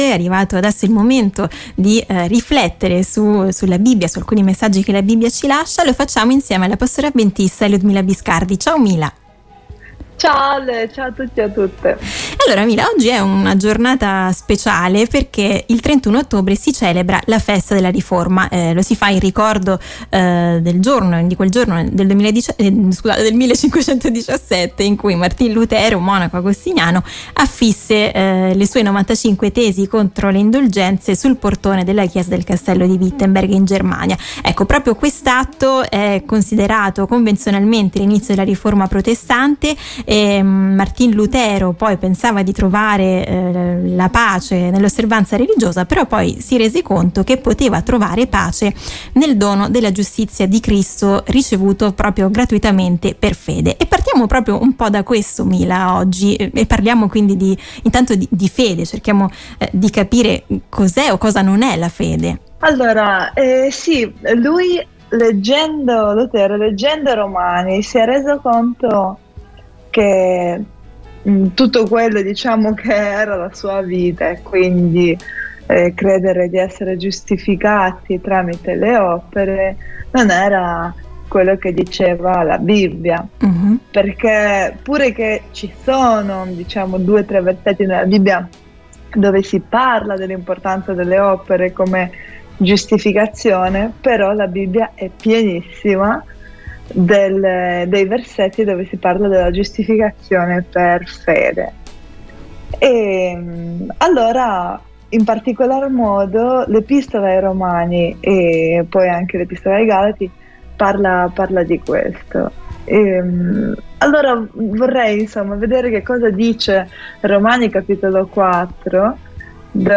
la pastora avventista